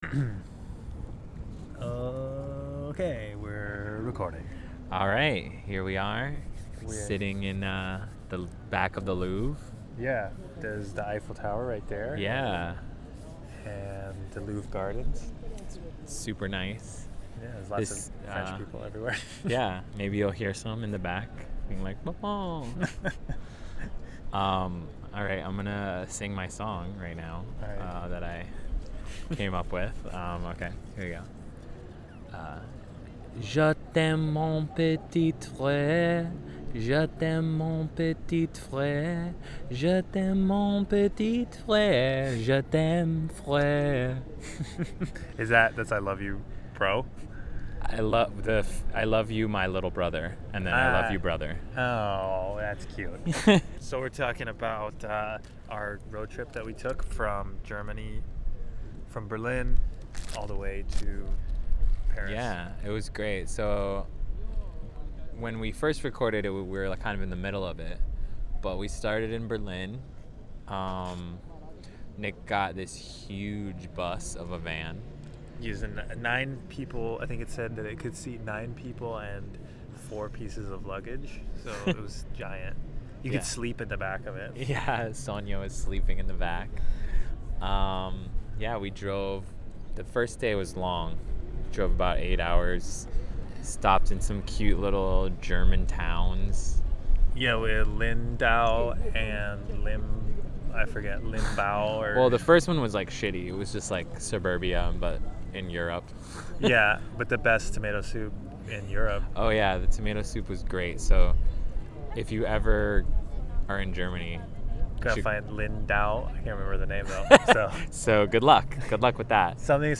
We recorded this podcast behind the Louvre at the end of our brother road trip through Europe. We discuss the road trip, the differences between Europe and America, and the Purge. Listen (not so) carefully for partying French people in the background.